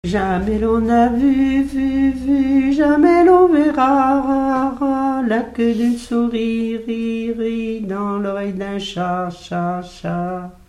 Mémoires et Patrimoines vivants - RaddO est une base de données d'archives iconographiques et sonores.
formulette enfantine : amusette
Pièce musicale inédite